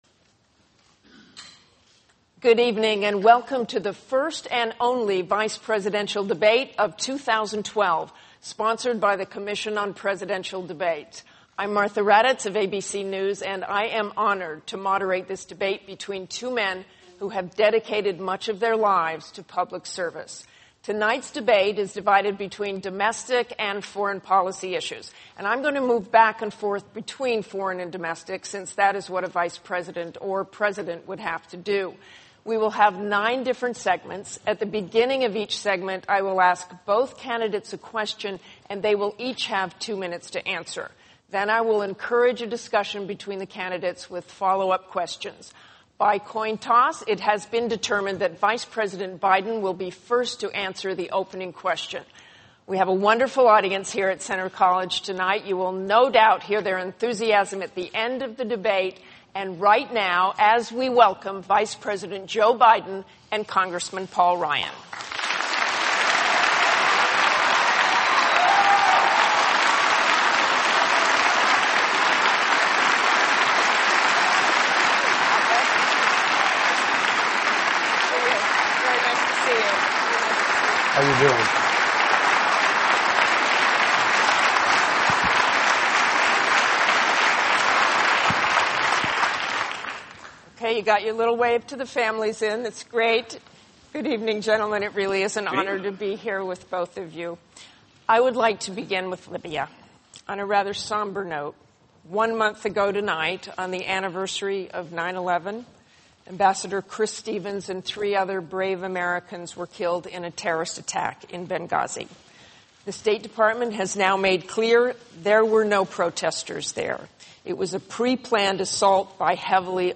Vice-Presidential Debate 10-11-12 - FULL - ENGLISH.Mp3